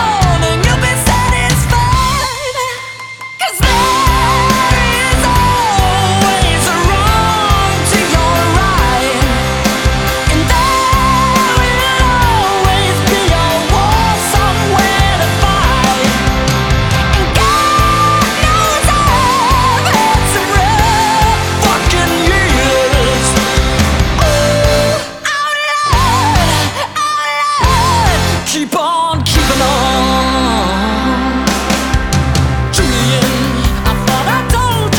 Жанр: Рок / Альтернатива / Метал